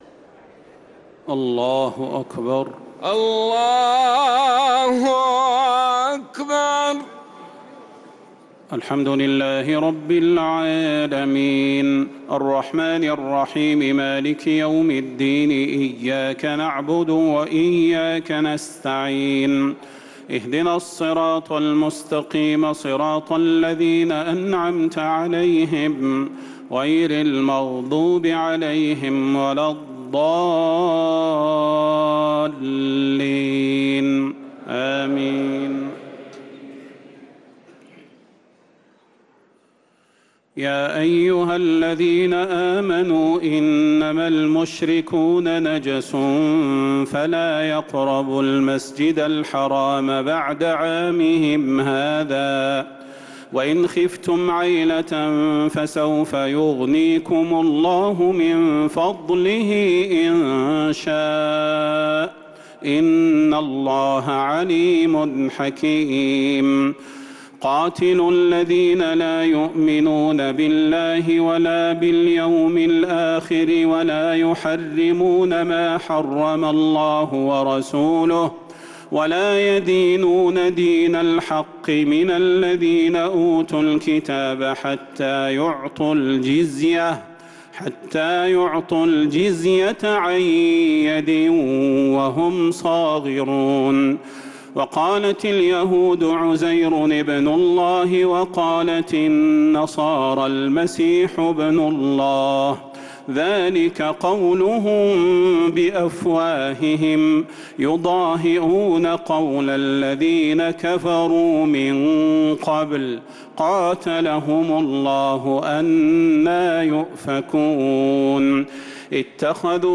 تراويح ليلة 13 رمضان 1446هـ من سورة التوبة (28-60) | Taraweeh 13th niqht Surat At-Tawba 1446H > تراويح الحرم النبوي عام 1446 🕌 > التراويح - تلاوات الحرمين